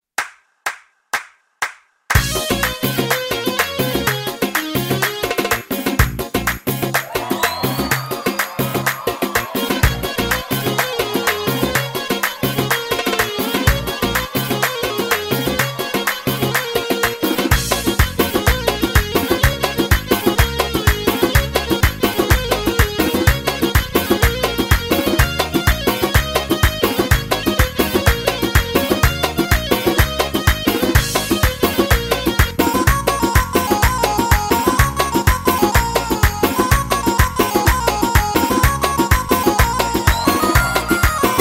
آهنگ شاد و عاشقانه